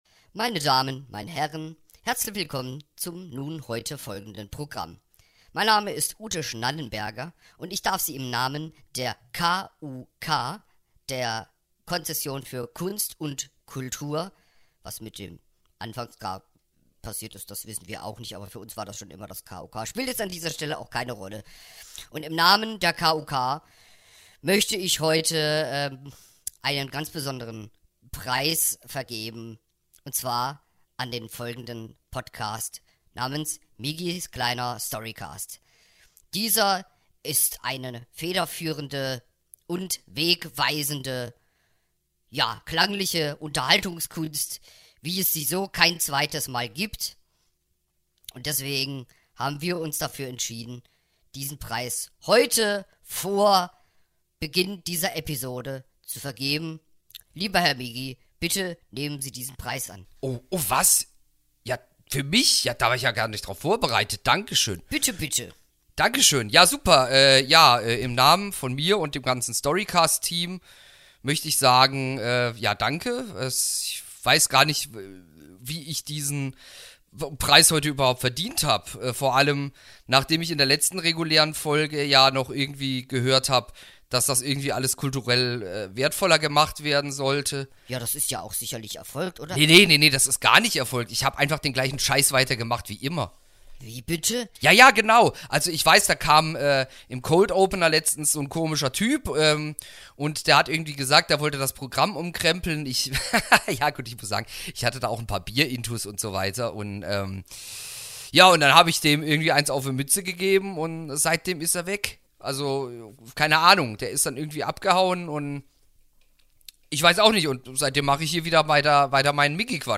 Egal, heute gibt es die Geschichte in erzählter Form.